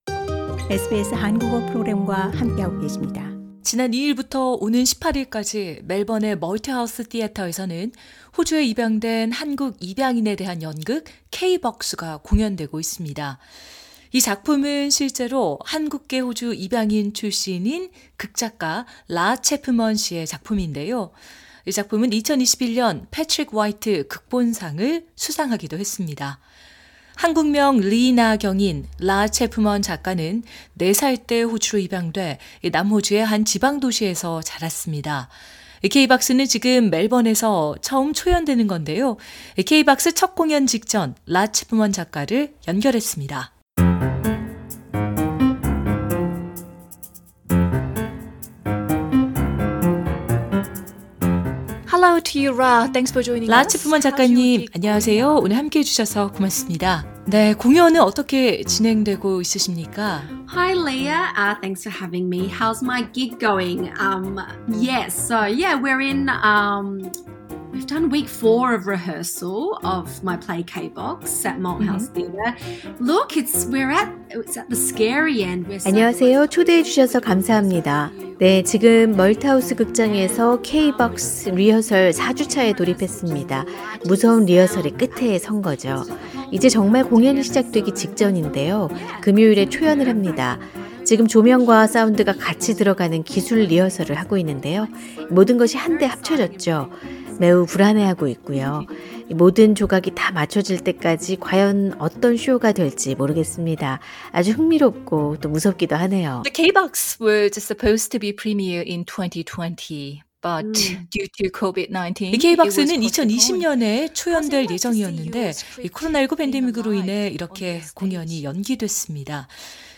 인터뷰: ‘35살 한국 입양인이 K-Pop을 만나면?’